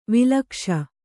♪ vilakṣa